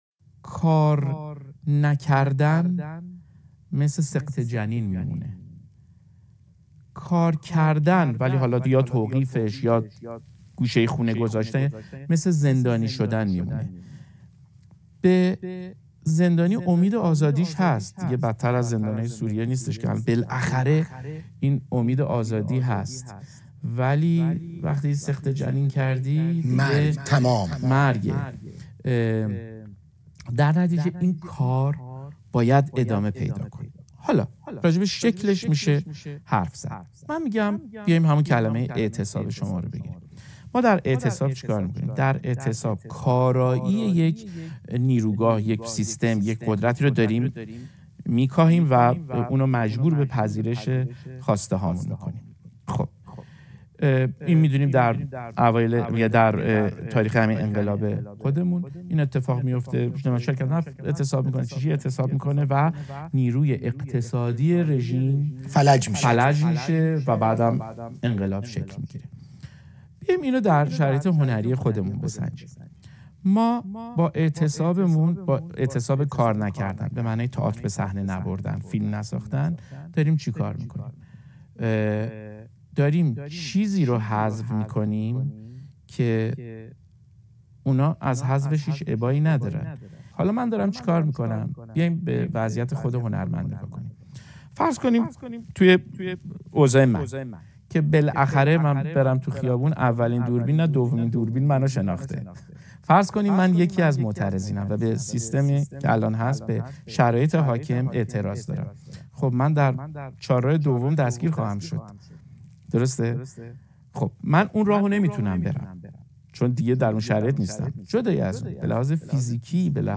(شنیداری)